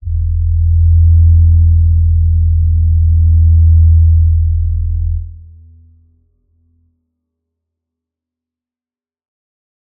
G_Crystal-E2-mf.wav